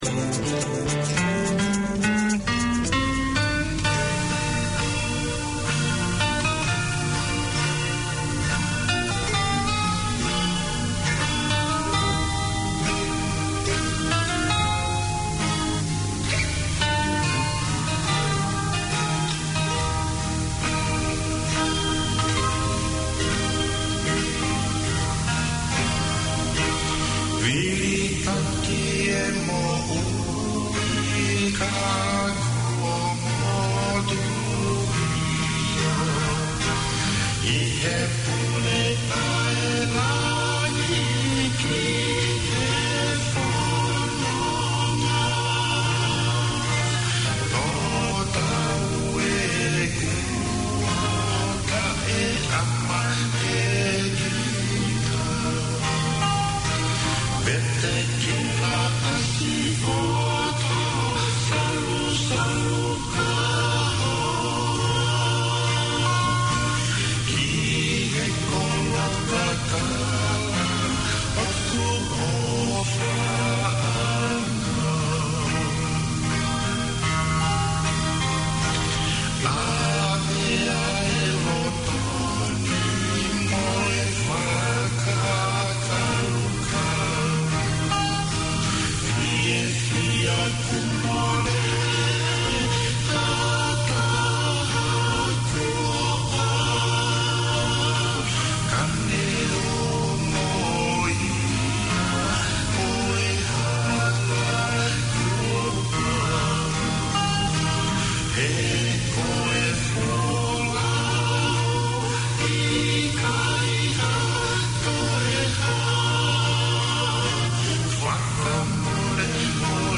Here’s the show that talks to young people and their parents about being young and Tongan in NZ. A chance for the generations to dialogue, hear what’s happening to and for youth in the community and hear Tongan music, traditional and modern.